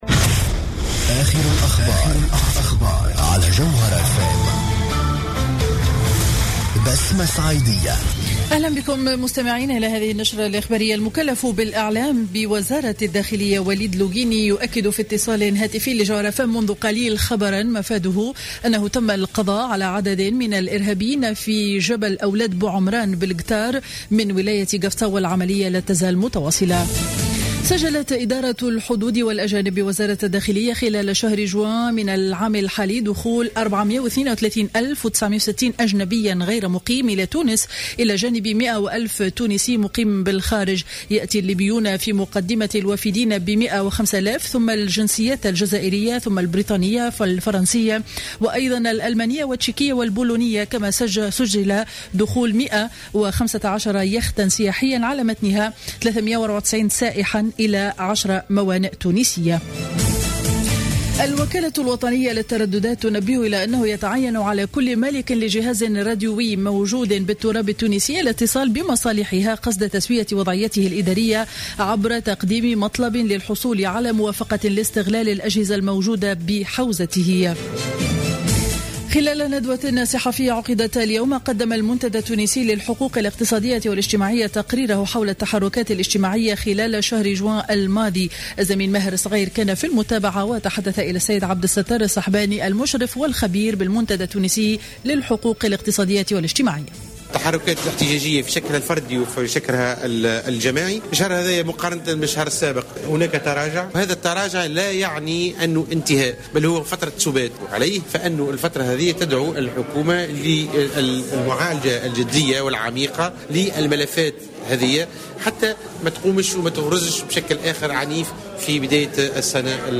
نشرة أخبار منتصف النهار ليوم الجمعة 10 جويلية 2015